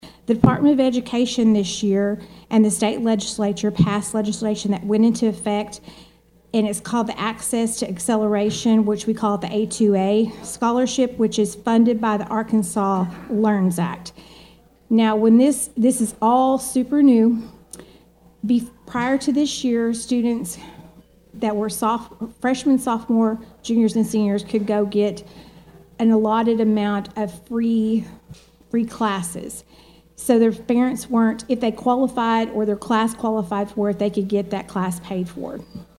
The Thayer Mammoth Spring Rotary met on Wednesday for its weekly meeting.